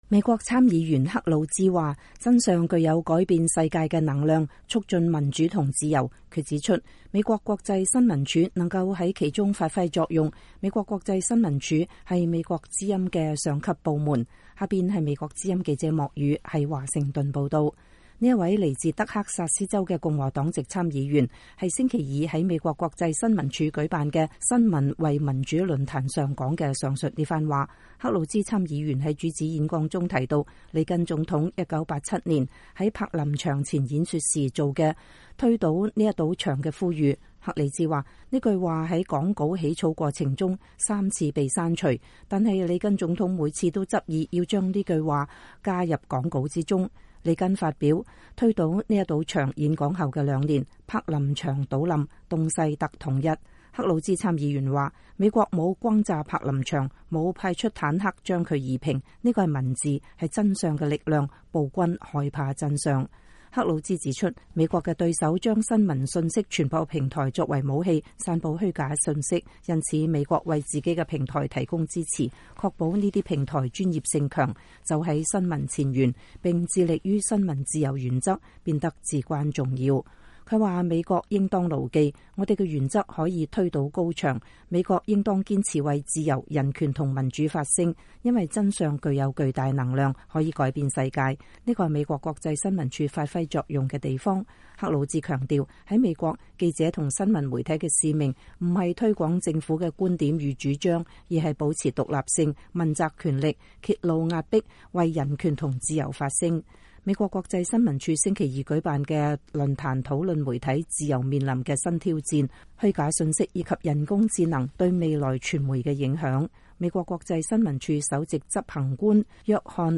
這位來自德克薩斯州的共和黨籍參議員是星期二在美國國際新聞署舉辦的“新聞為民主論壇”（Media 4 Democracy Forum）上說的上述這番話。